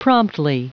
Prononciation du mot promptly en anglais (fichier audio)